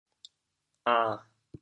国际音标 [ã]